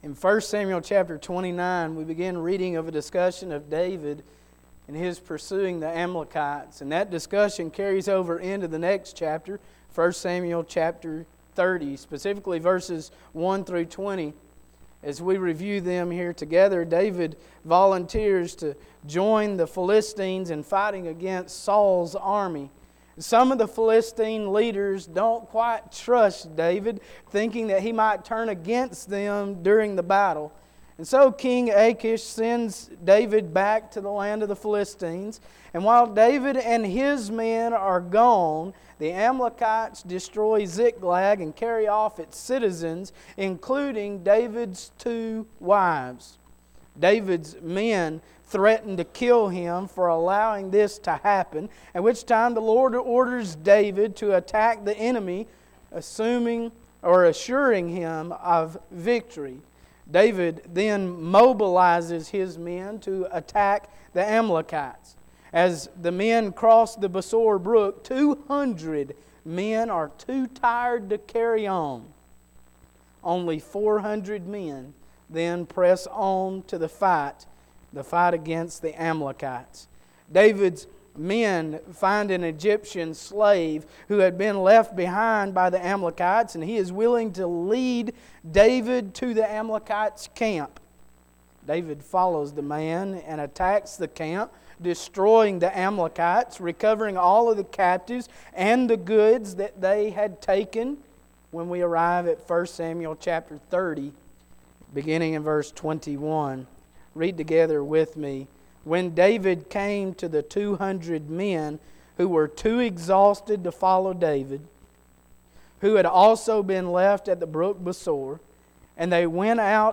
Acts 11:19-26 Service Type: Sunday Morning